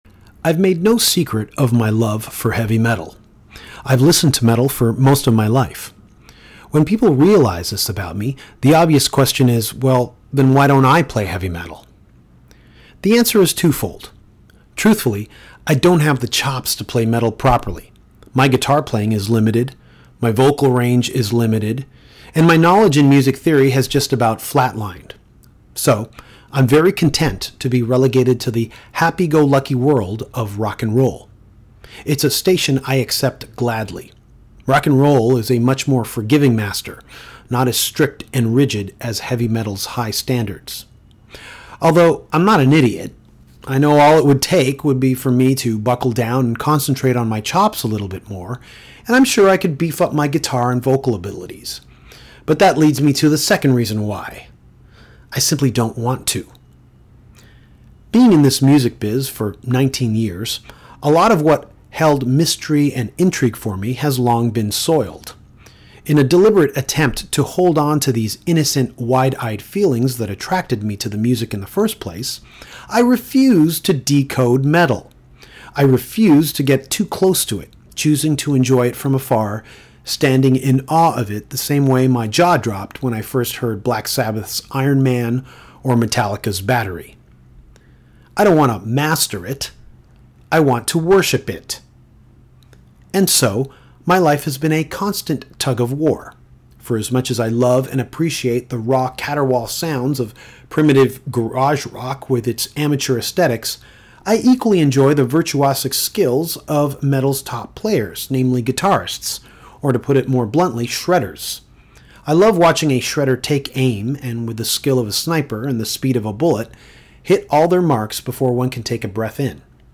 Nita Strauss, guitarist in The Alice Cooper band, joins Danko to talk about Marty Friedman, That Metal Show, Bob Ezrin, Ralph Macchio, Steve Vai and Crossroads, Jermaine Jackson, Glen Sobel, The L.A. Kiss, Paul Stanley and, of course, Alice Cooper.